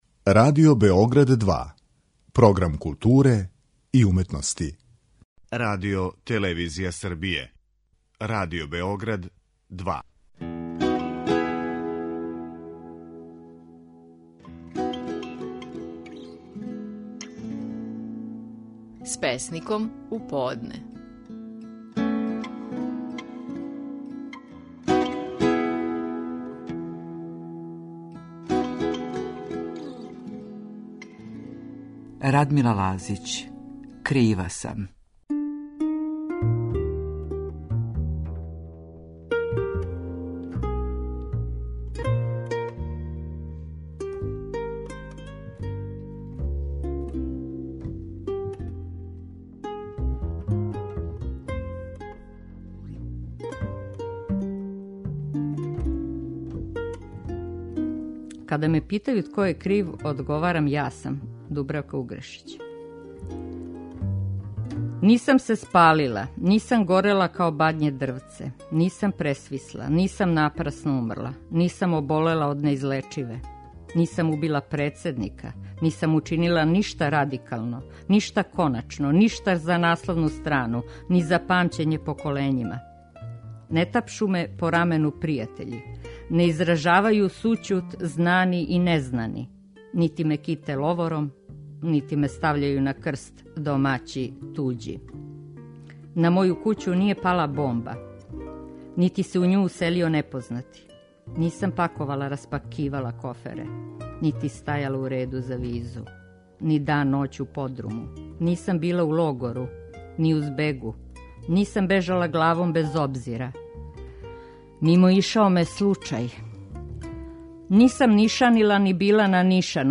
Наши најпознатији песници говоре своје стихове
Радмила Лазић говори своју песму "Kрива сам".